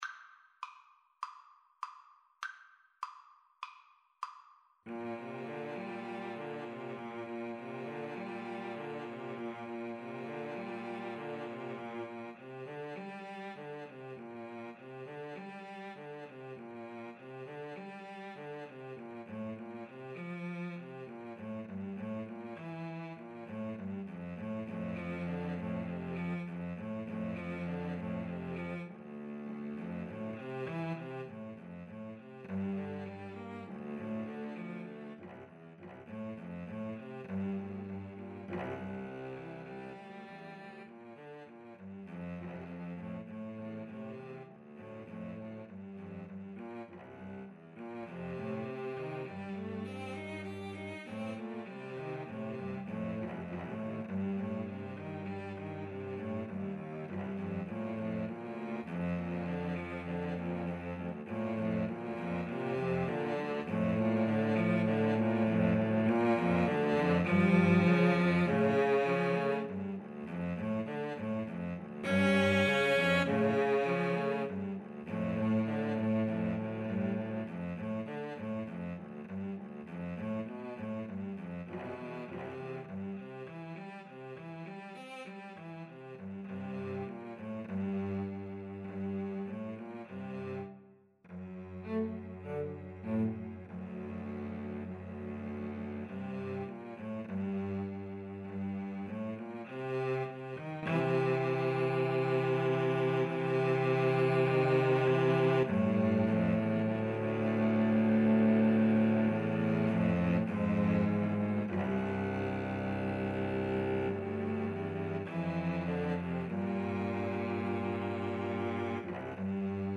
Andantino sans lenteur (View more music marked Andantino)
4/4 (View more 4/4 Music)
Cello Trio  (View more Intermediate Cello Trio Music)
Classical (View more Classical Cello Trio Music)